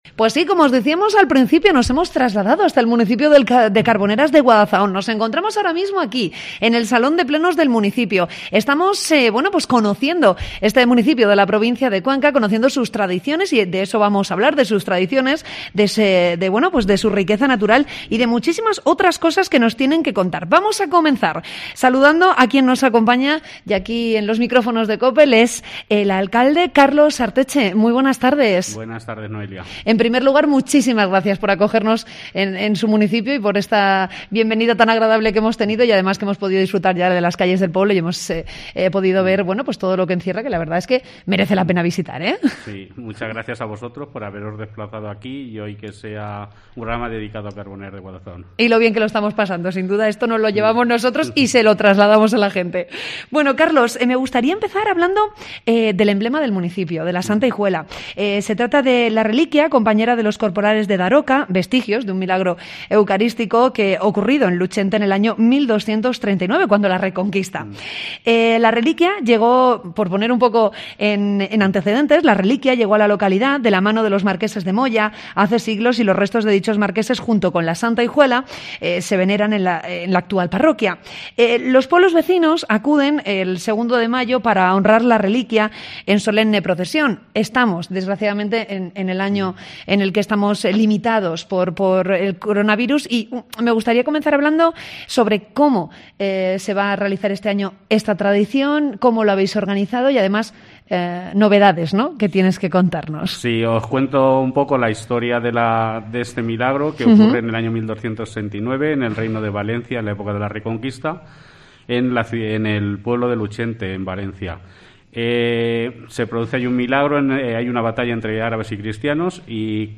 Entrevista con el alcalde de Carboneras de Guadazaón, Carlos Arteche